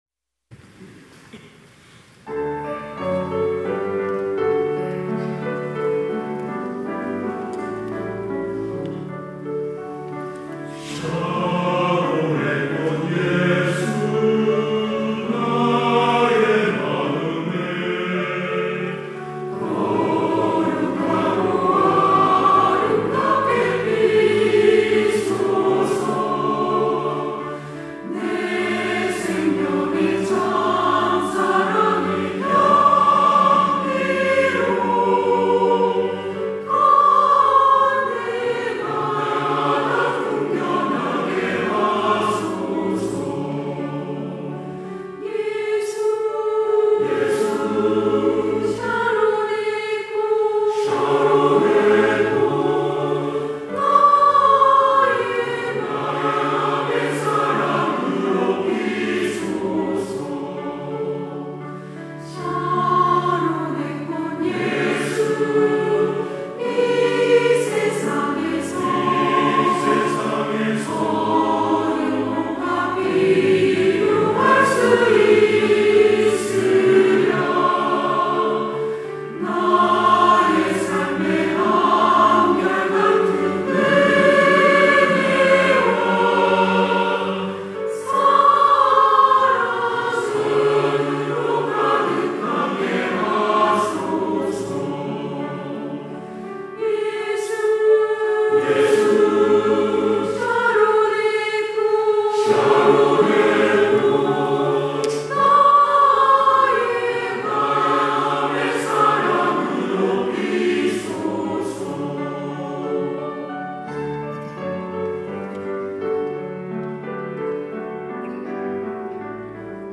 시온(주일1부) - 샤론의 꽃 예수
찬양대